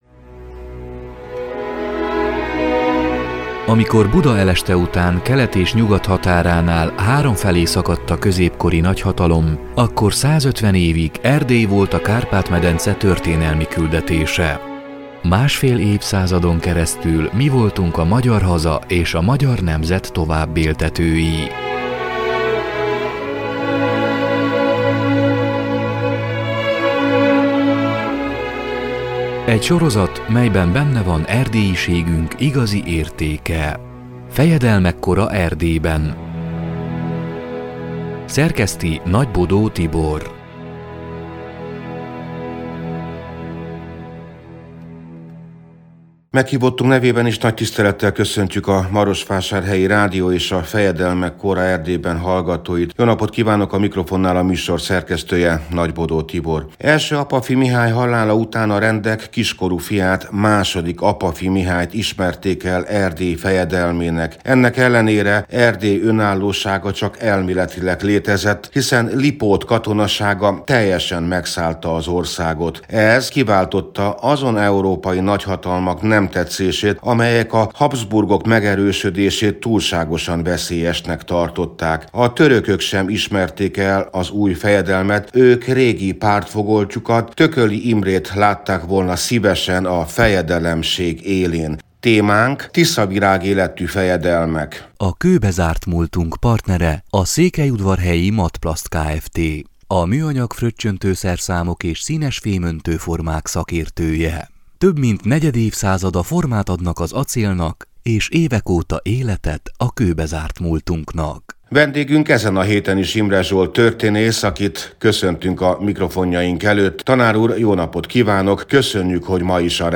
(elhangzott: 2021. július 24-én, a szombat déli harangszó után)